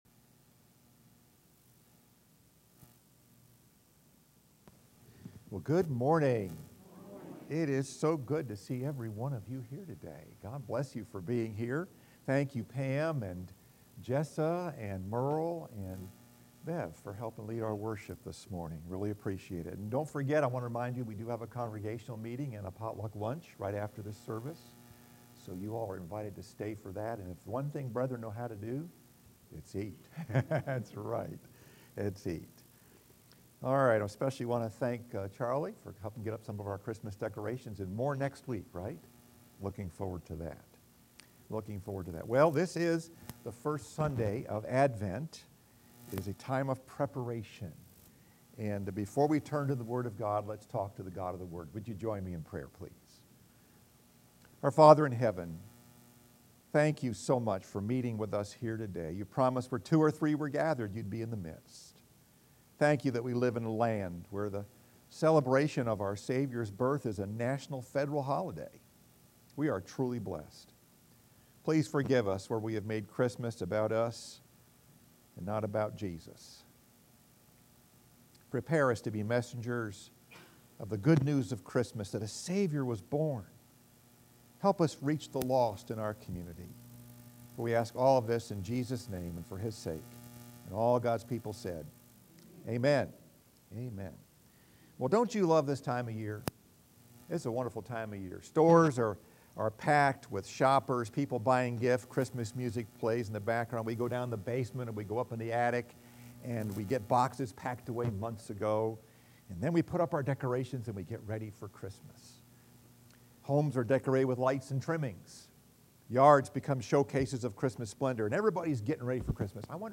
Message: “Get Ready for Christmas” Scripture: Matthew 5:1-8 FIRST SUNDAY of ADVENT